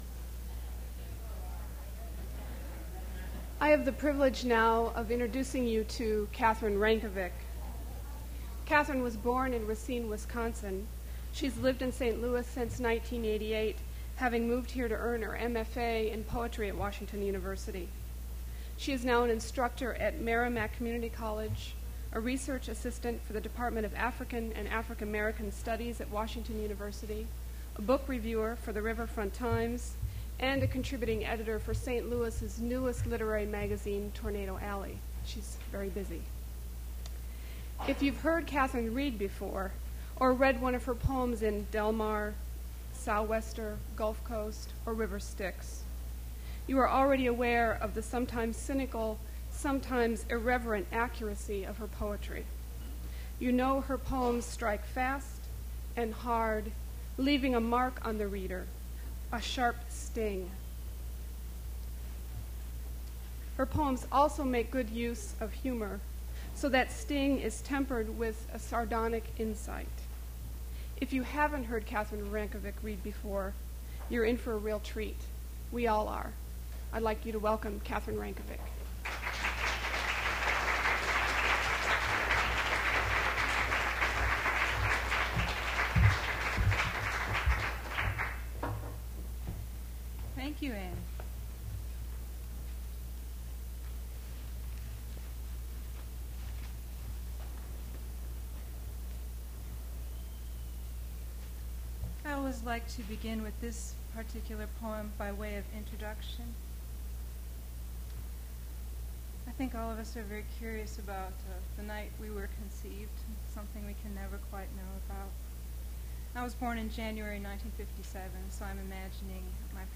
mp3 edited access file was created from unedited access file which was sourced from preservation WAV file that was generated from original audio cassette. Language English Identifier CASS.743 Series River Styx at Duff's River Styx Archive (MSS127), 1973-2001 Note Recording is very low quality. Had to be amplified but the subsequent hiss is distracting.